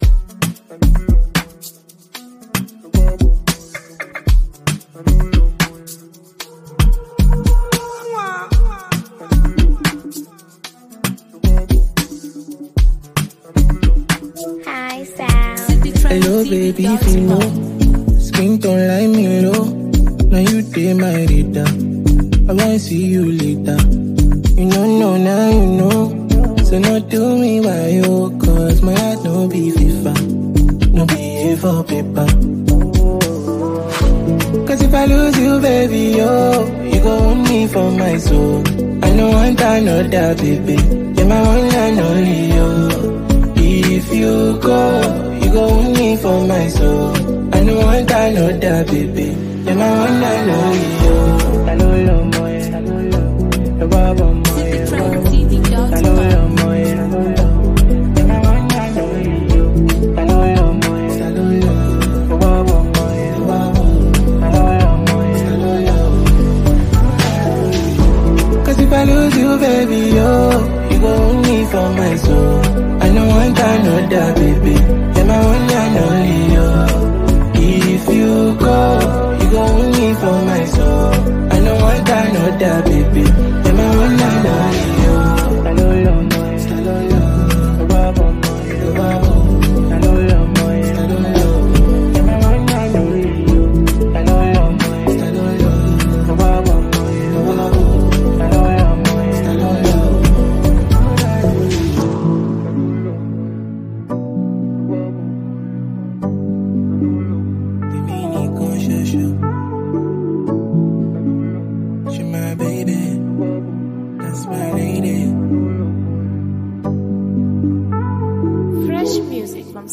a blend of sultry vocals and classic Afrobeats percussion